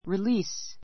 release A2 rilíːs リ り ー ス 動詞 ❶ 釈放 しゃくほう する; 放す The hostages were released.